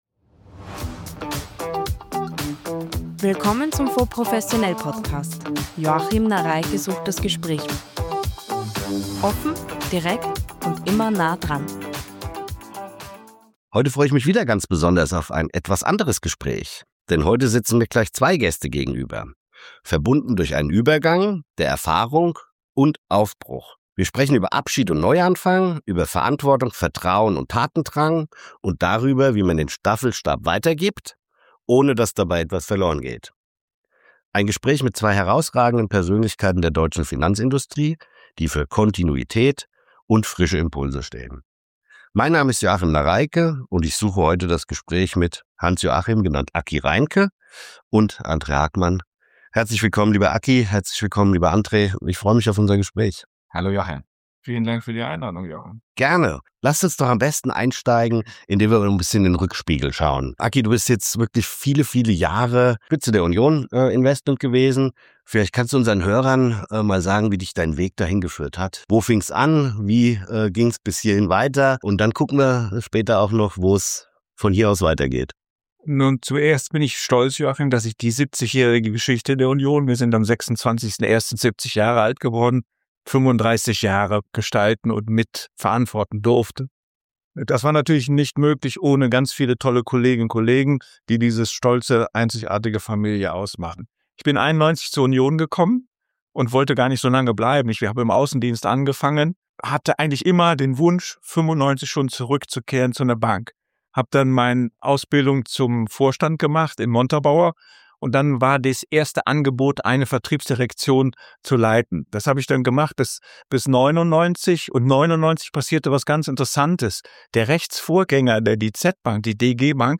Gespräch
mit viel Klartext und rheinischer Note